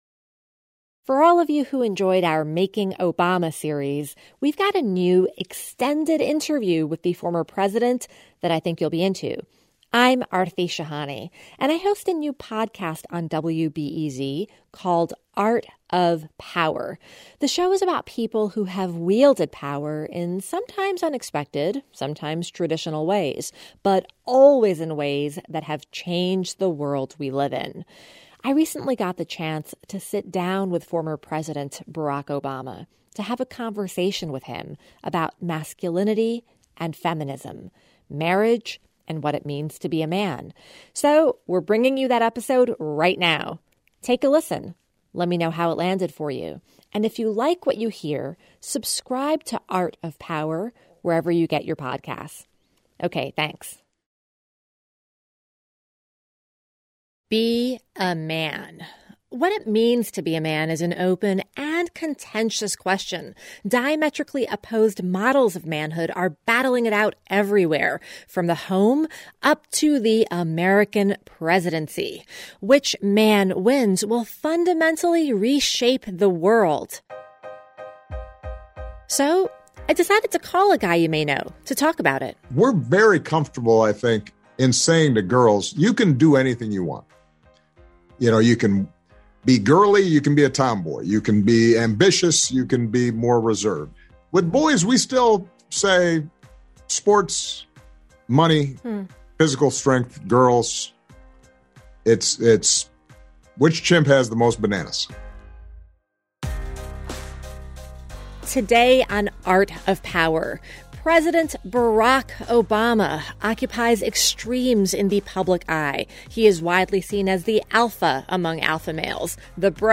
We're bringing you this exclusive interview with the former president as a bonus to the Making Obama podcast series. Hear the former president explain his experience balancing ambition with family obligations and how he wants to expand the definition of what it means to be a man.